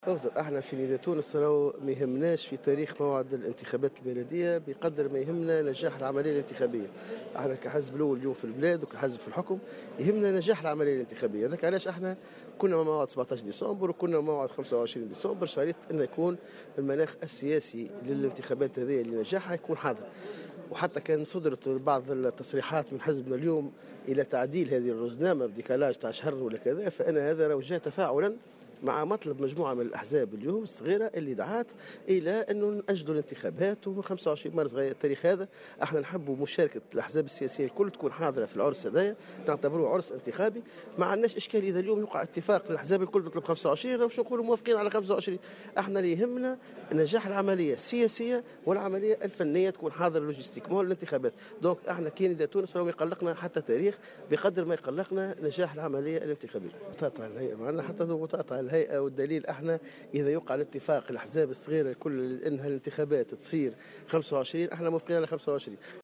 وأوضح في تصريح لمراسل "الجوهرة أف أم" قبل انطلاق اللقاء التشاوري الذي سيجمع الهيئة بعدد من الأحزاب السياسية أن الدعوة لتعديل الرزنامة جاء تفاعلا مع مطلب عدد من الأحزاب الصغيرة.